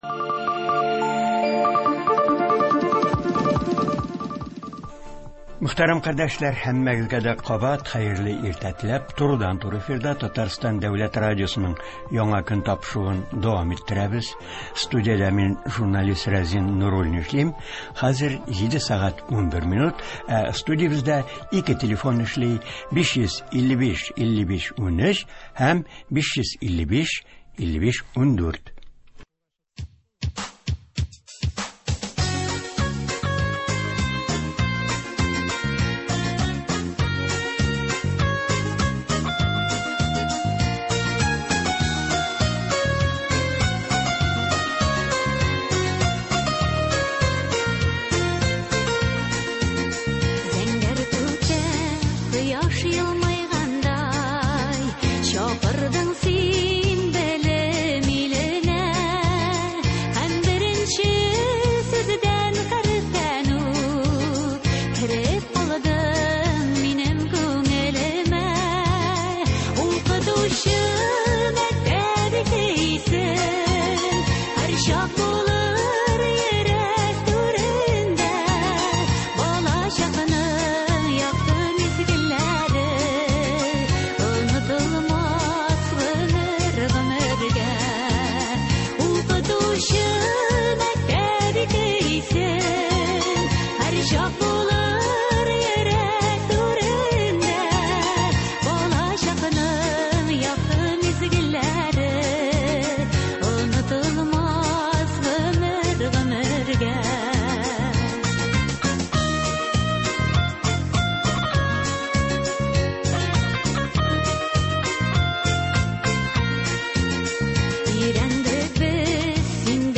Татарстан мәгариф системасы елны ничек төгәлләде? Болар хакында турыдан-туры эфирда Татарстан республикасы мәгариф һәм фән министры Илсур Гәрәй улы Һадиуллин сөйләячәк, яңа уку елына әзерлек мәсьәләләренә тукталачак, тыңлаучылар сорауларына җавап бирәчәк.